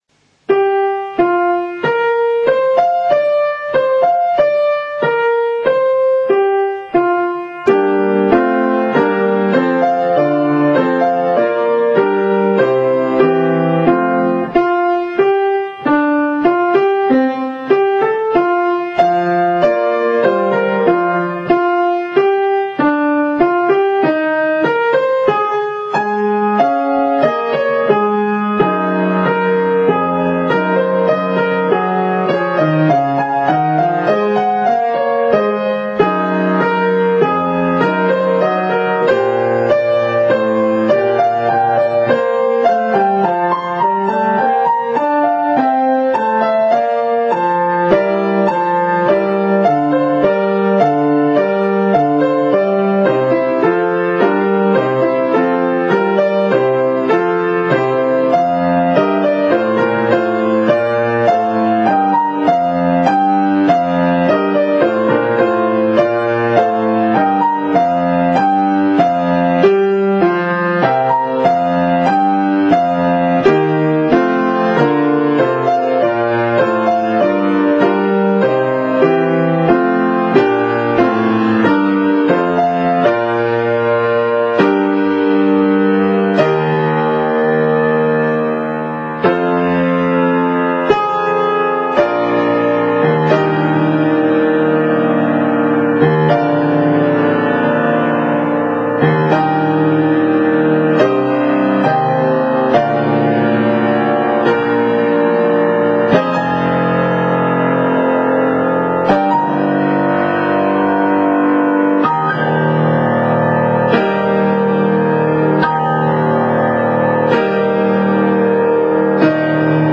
今回演奏する曲は、「プロムナード」と「キエフの大門」をメドレー風につなげて、少し弾きやすくアレンジしてあります。